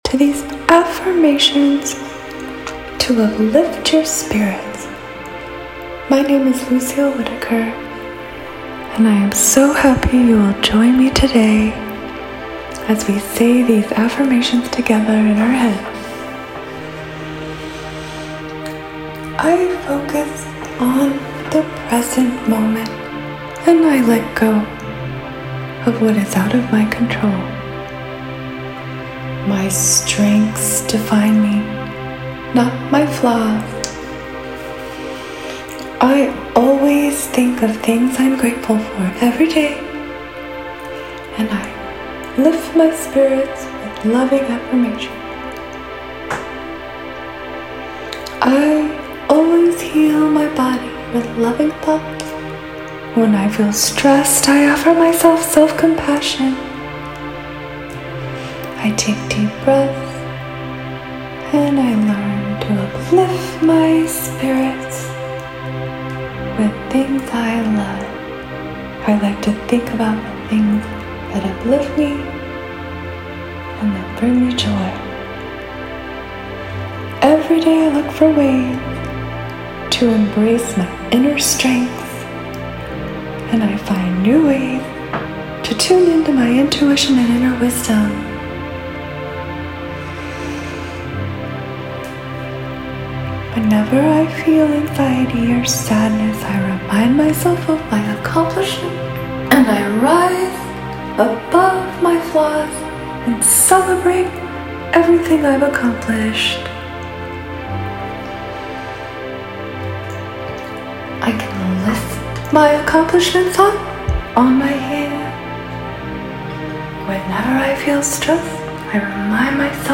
***_uplifting_affirmations.mp3